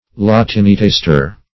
Search Result for " latinitaster" : The Collaborative International Dictionary of English v.0.48: Latinitaster \La*tin"i*tas`ter\, n. [Cf. Poetaster .]